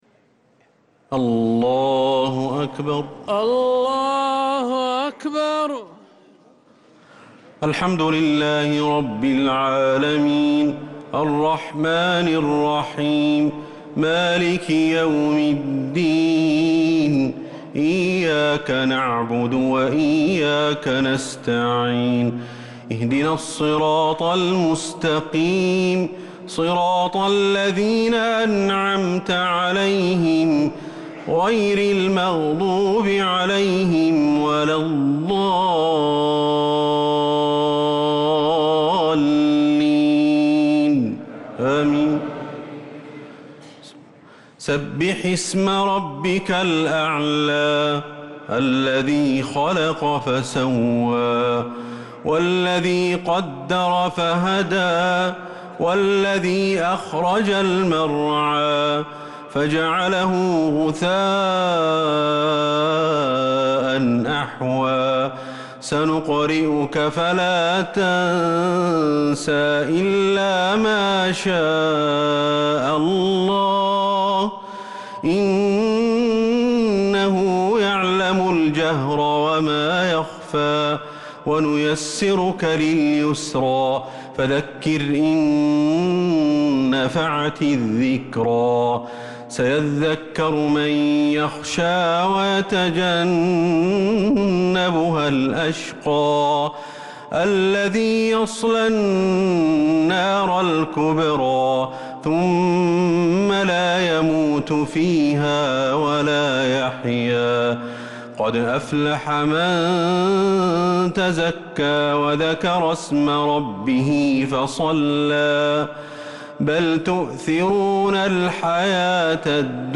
صلاة الشفع و الوتر ليلة 26 رمضان 1446هـ | Witr 26th night Ramadan 1446H > تراويح الحرم النبوي عام 1446 🕌 > التراويح - تلاوات الحرمين